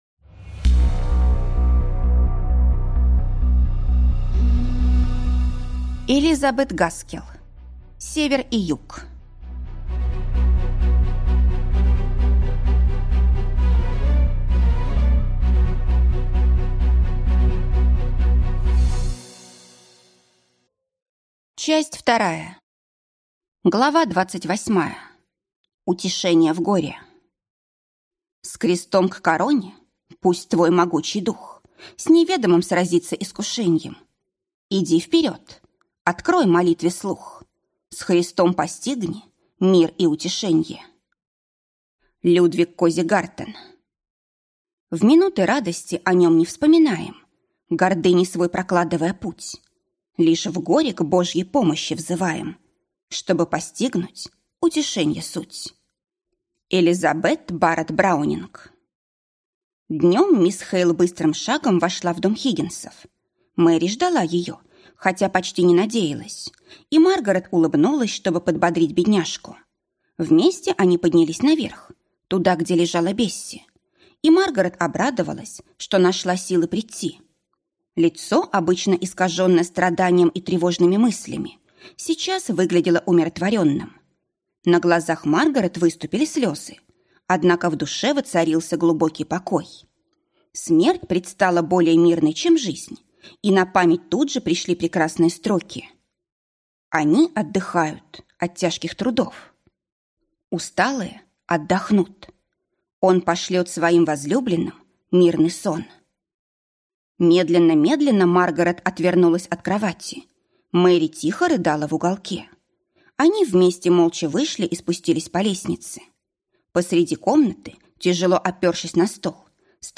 Аудиокнига Север и Юг (Часть 2) | Библиотека аудиокниг